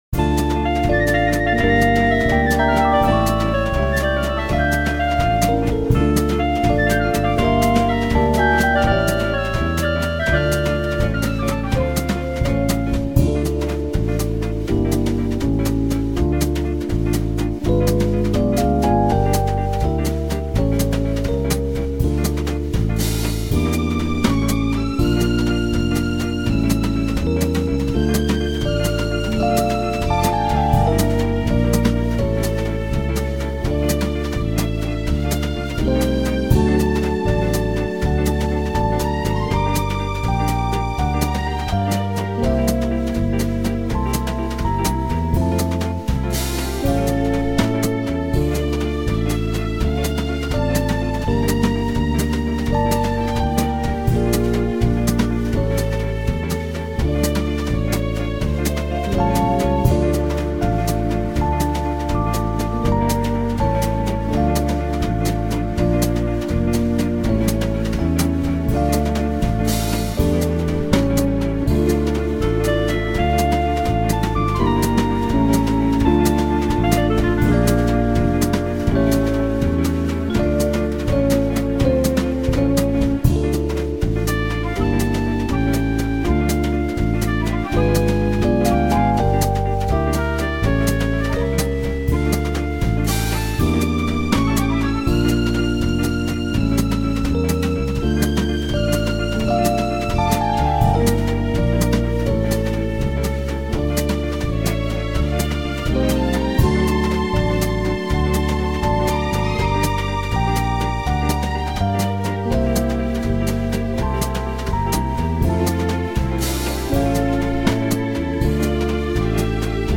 Слушать или скачать минус